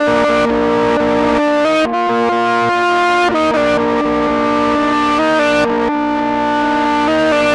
Track 10 - Guitar 07.wav